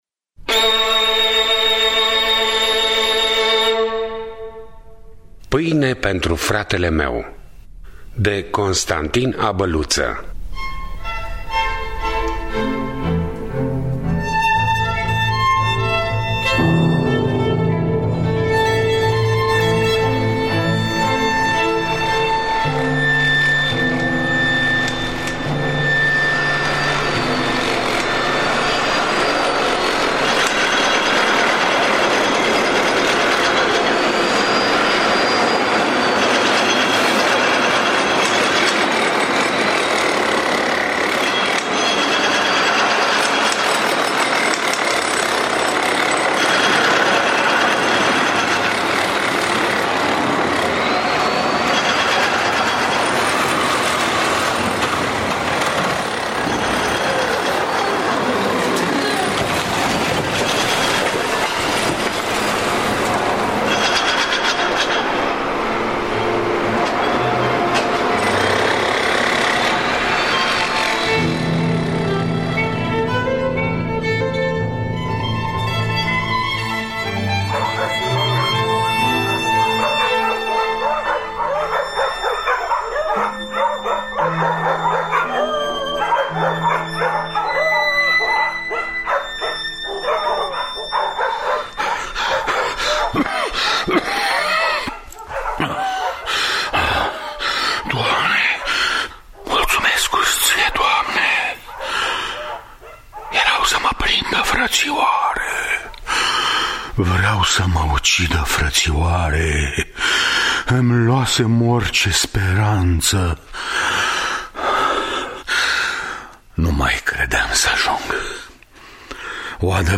Pâine pentru fratele meu de Constantin Abăluță – Teatru Radiofonic Online